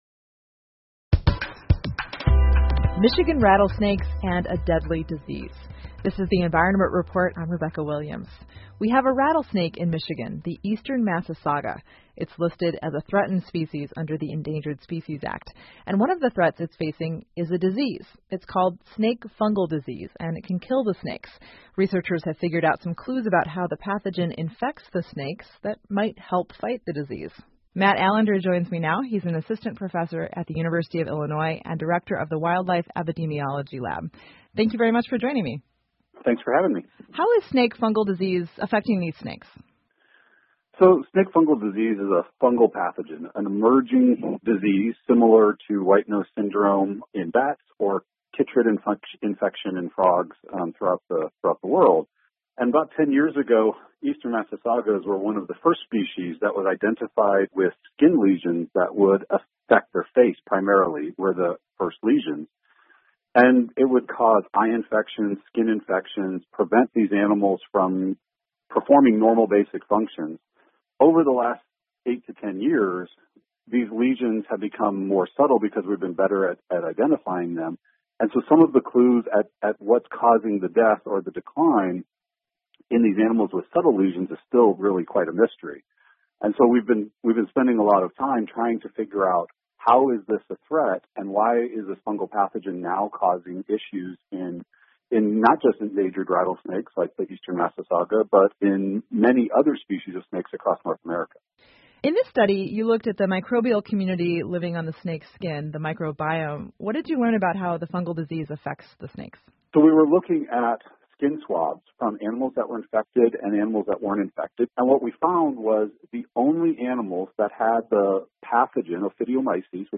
密歇根新闻广播 影响密歇根响尾蛇一种疾病的新线索 听力文件下载—在线英语听力室